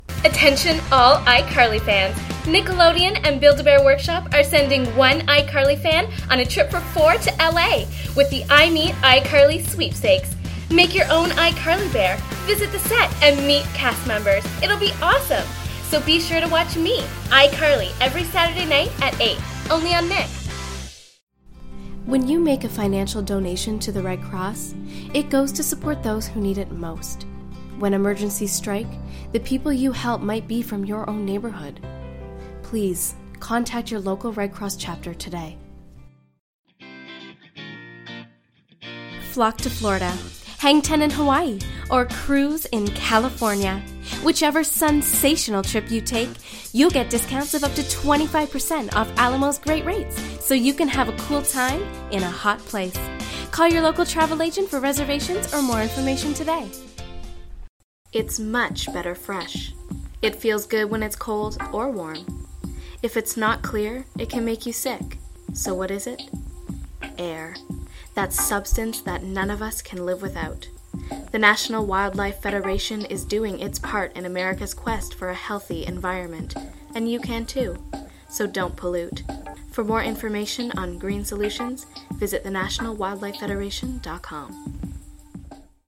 Commercials - EN